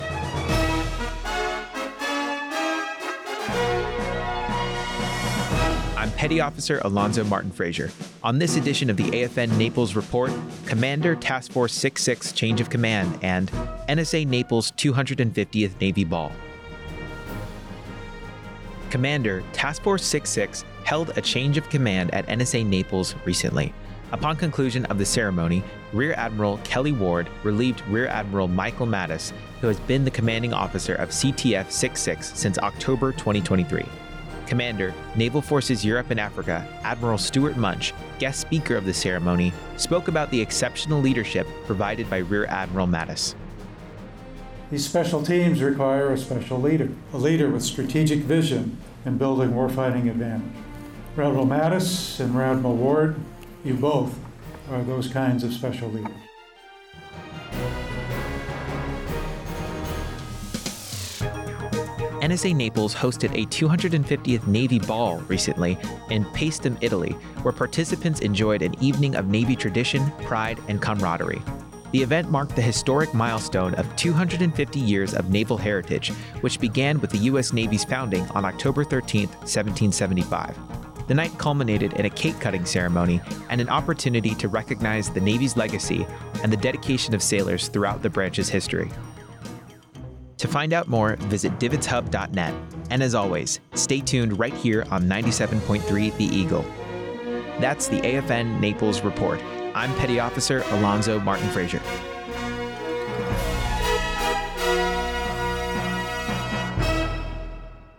AFN Naples Radio News - CTF 66 Change of Command and NSA Naples 250 Navy Ball